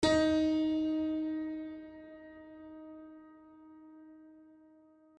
HardPiano